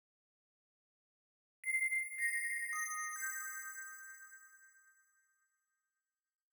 13 Bells PT 1-2.wav